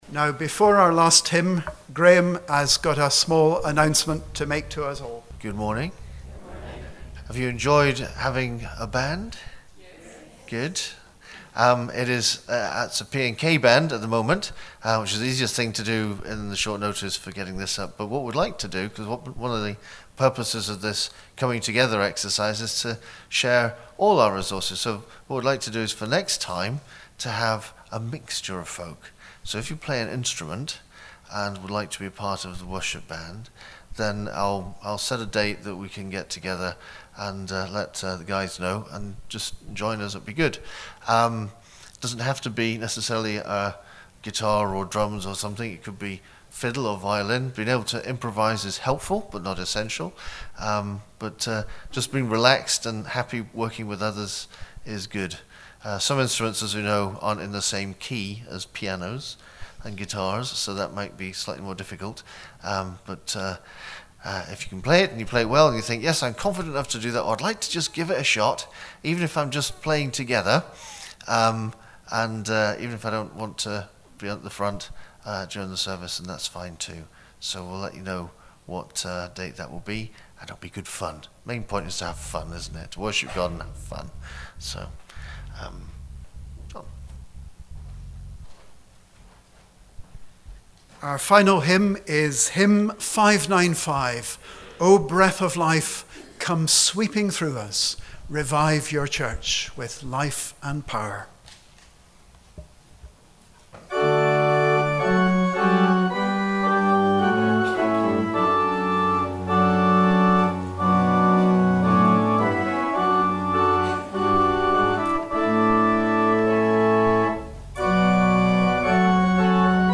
The congregation
Joint Church of Scotland Service - 1 March 2020